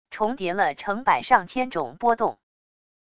重叠了成百上千种波动wav音频